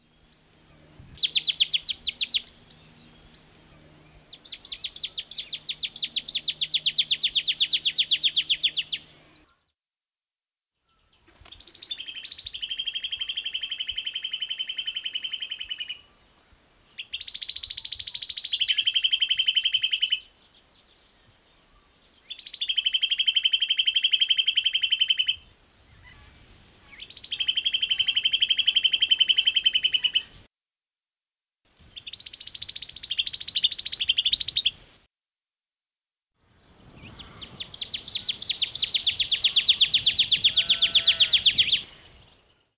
In a revegetated gully at the back of our place there is a wealth of bird chatter at present.
Click on the audio icons below to hear their resounding calls which are well worth a listen.
White-winged Triller:
As a songster, the Rufous Whistler probably wins the day, but the White-winged Triller is certainly no slouch in the vocal stakes.
white-winged-triller.mp3